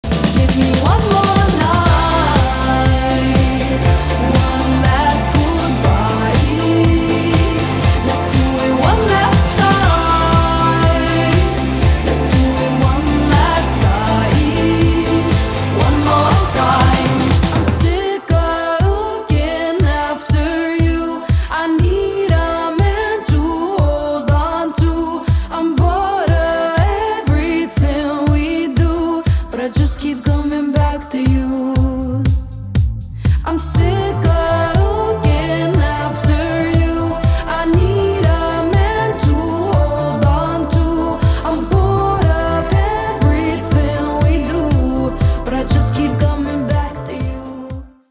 • Качество: 320, Stereo
красивые
женский вокал